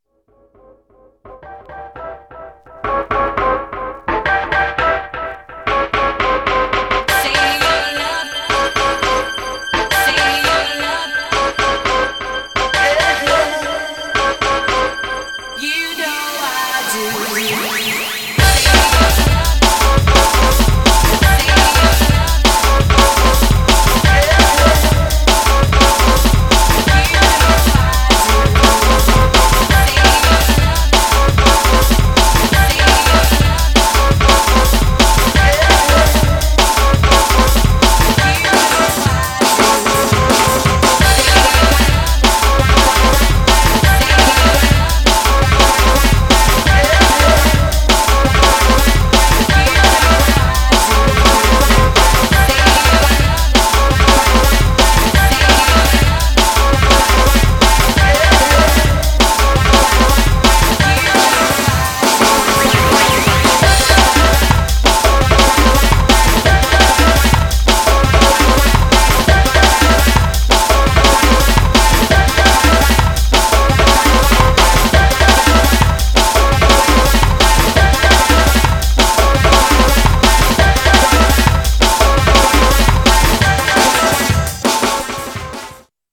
Styl: Drum'n'bass, Jungle/Ragga Jungle
* hraje velmi dobře